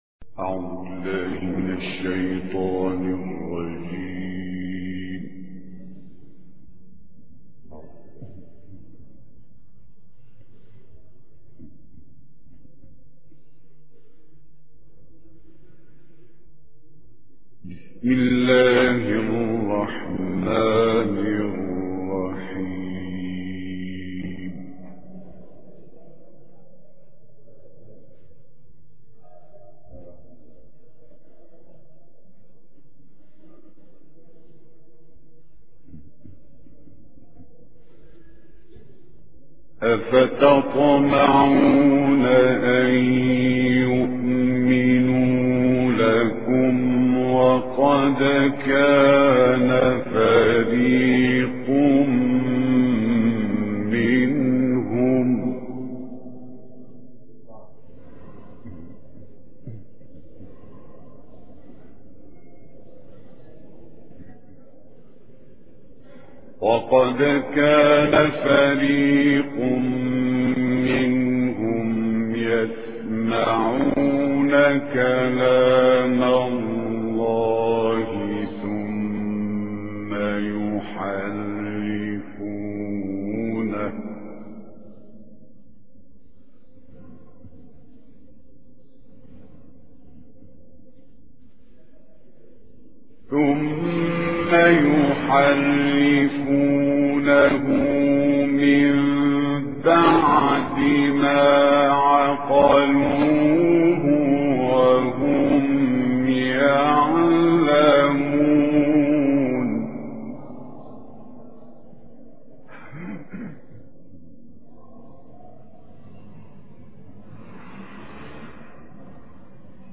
ملف صوتی ما تيسر من سورة البقرة - 1 بصوت إبراهيم عبدالفتاح الشعشاعي